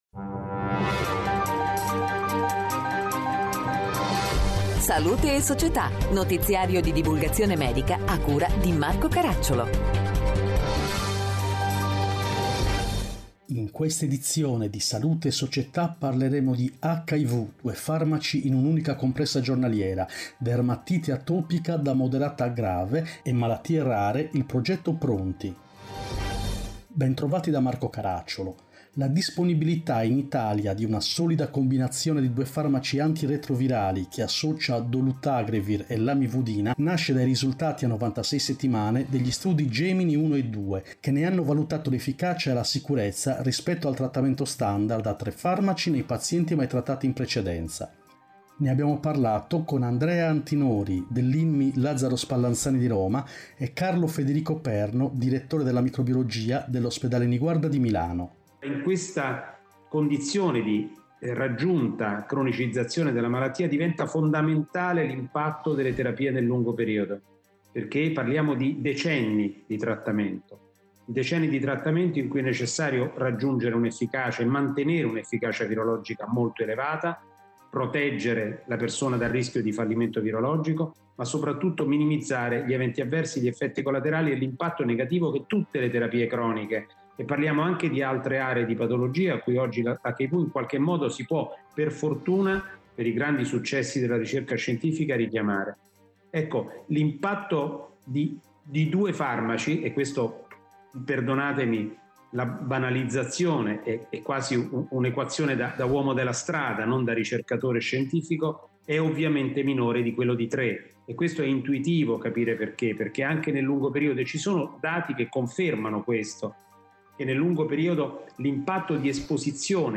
In questa edizione: 1. HIV, Due farmaci in unica compressa giornaliera 2. Dermatite atopica da moderata a grave 3. Malattie rare, il progetto “PRONTI” Interviste